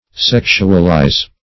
Sexualize \Sex"u*al*ize\, v. t.